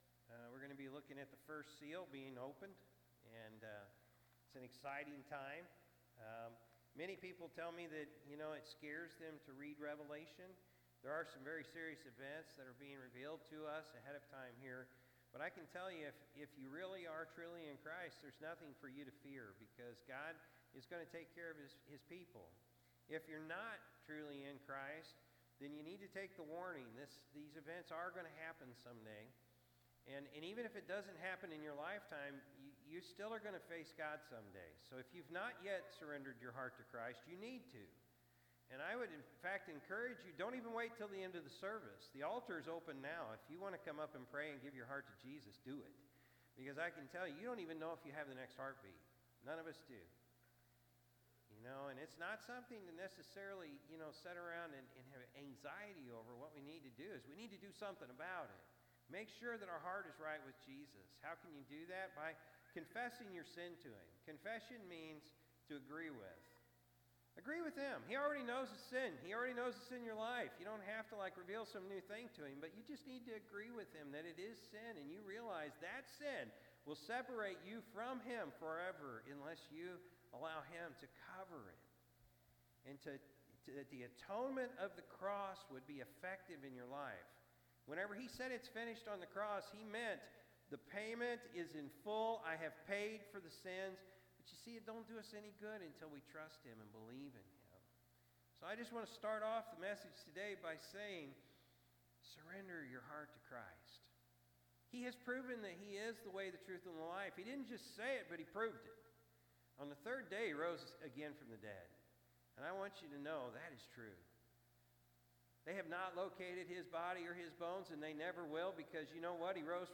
August-4-2024-Morning-Service.mp3